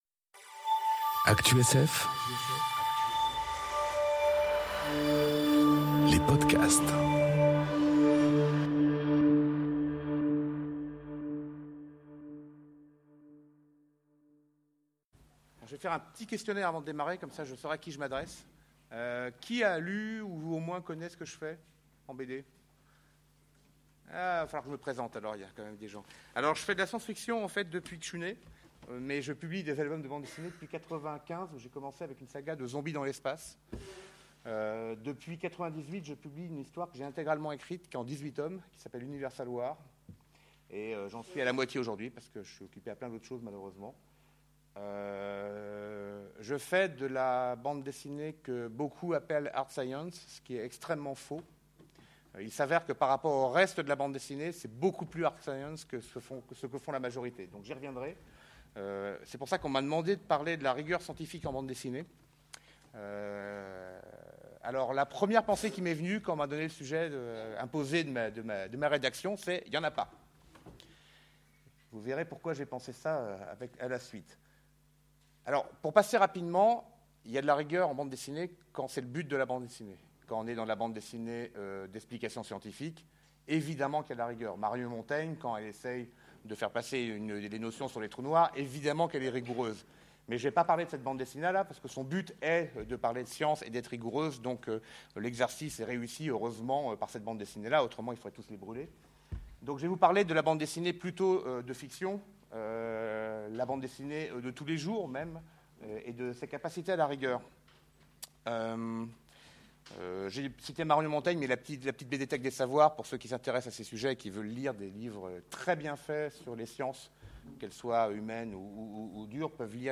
Conférence Rigueur scientifique et bande dessinée enregistrée aux Utopiales 2018